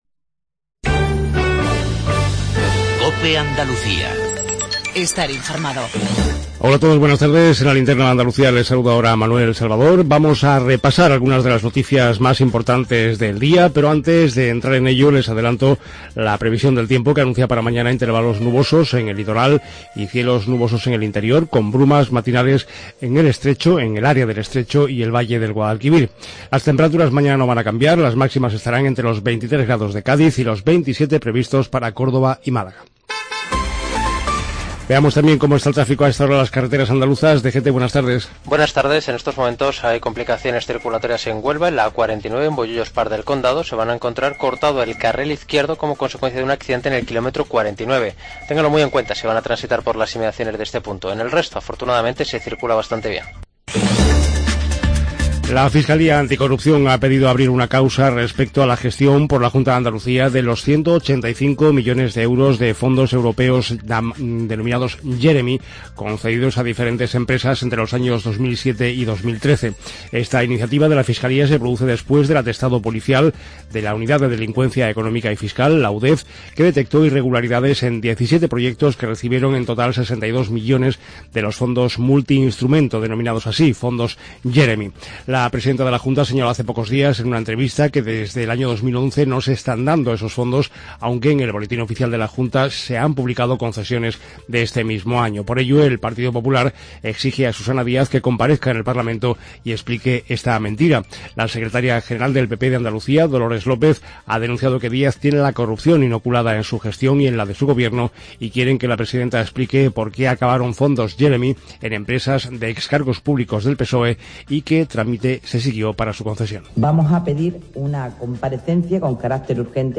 INFORMATIVO REGIONAL TARDE COPE ANDALUCIA